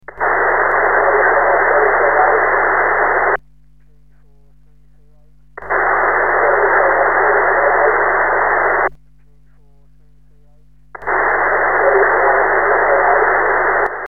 Echoes are really good with the new SSPA and approx 30W at the feed.
Hyperlinks are to audio clips of signals